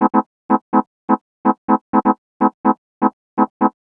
cch_synth_repeater_125_F#m.wav